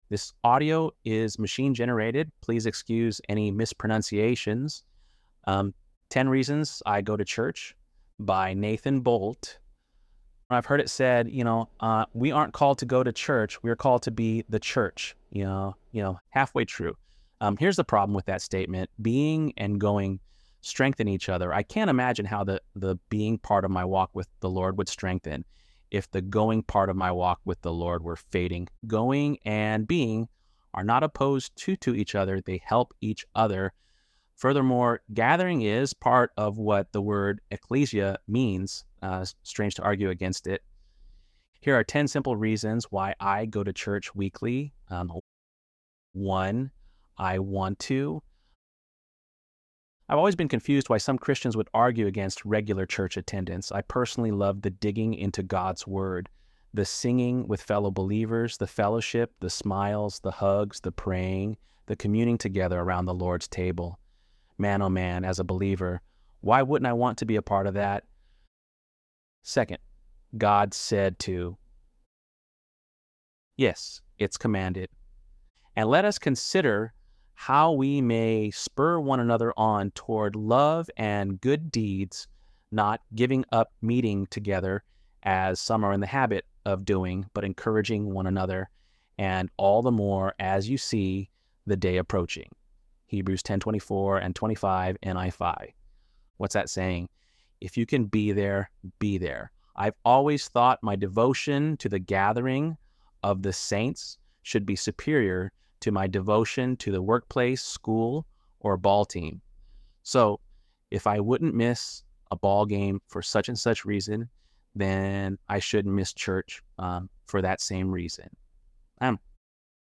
ElevenLabs_5.27_10_Reasons.mp3